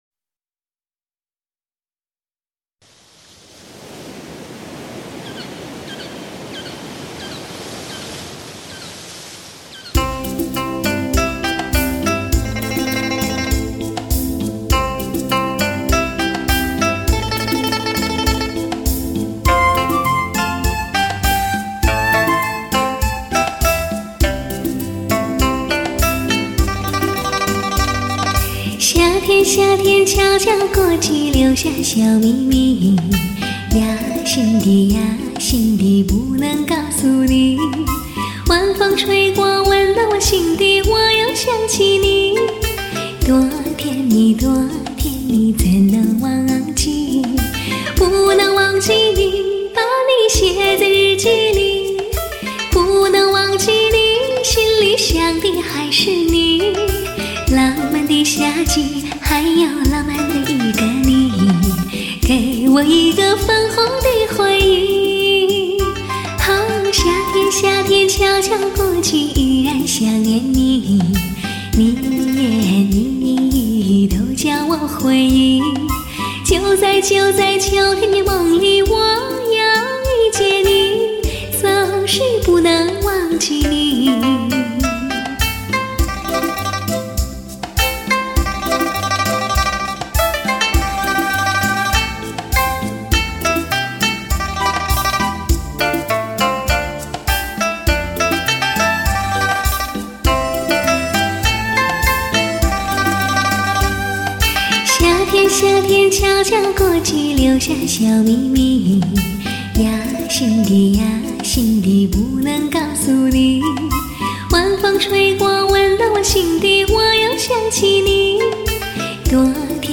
2CD K2HD 黑胶